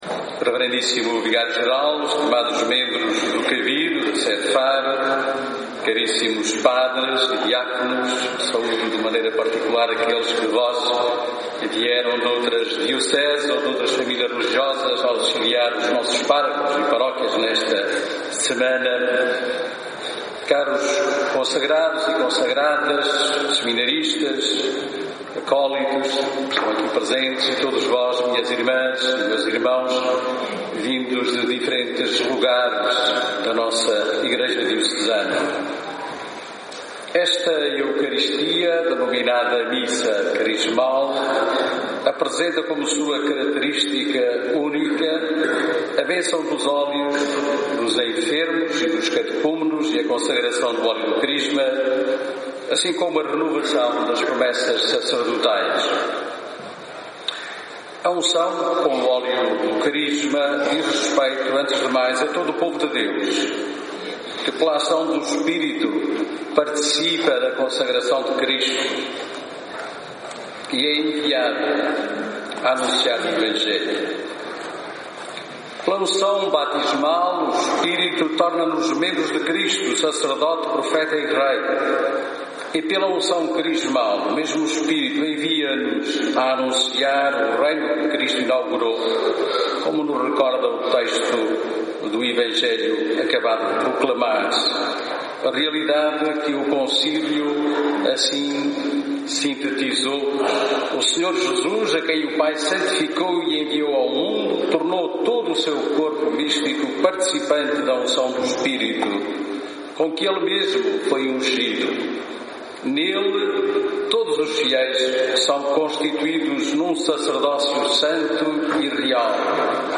O bispo do Algarve destacou esta manhã aos sacerdotes na Missa Crismal que a “sedução”, a “intimidade” e a “confiança” são a base do seguimento a Jesus Cristo.
Homilia_missa_crismal_2017.mp3